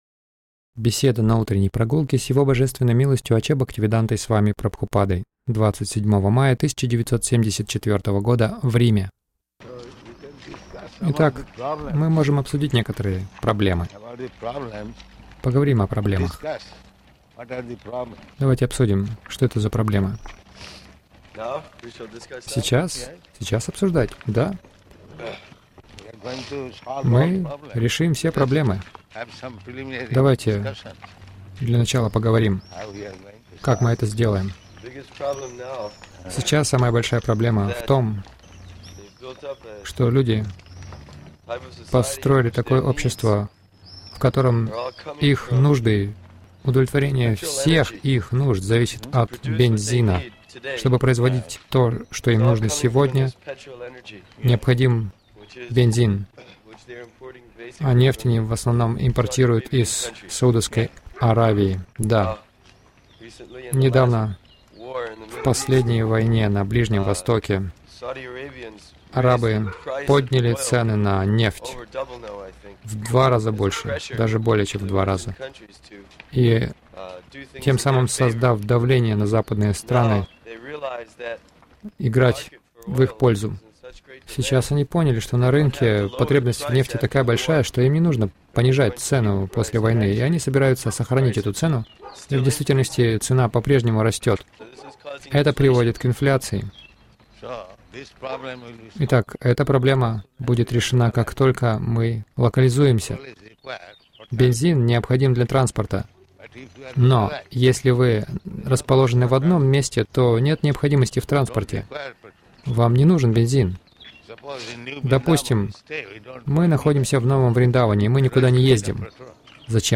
Утренние прогулки — Поговорим о проблемах
Милость Прабхупады Аудиолекции и книги 27.05.1974 Утренние Прогулки | Рим Утренние прогулки — Поговорим о проблемах Загрузка...